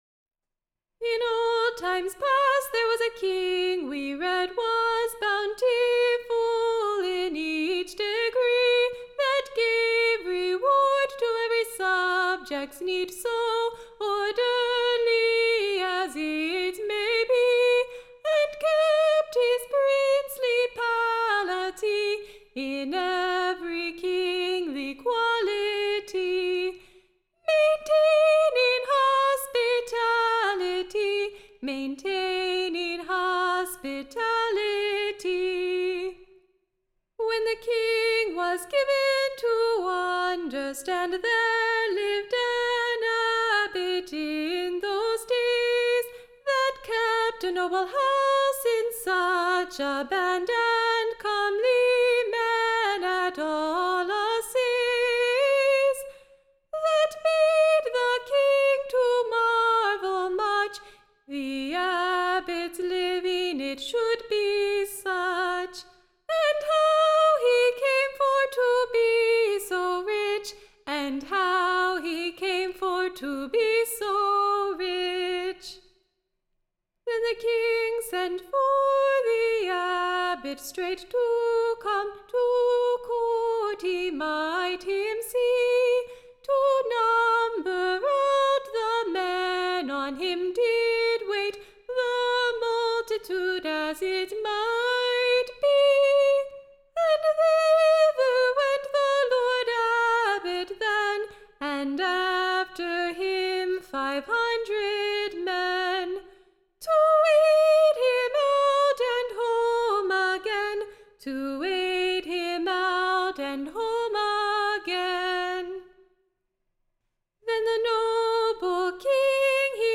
Recording Information Ballad Title The Old ABBOT / AND / King OLFREY.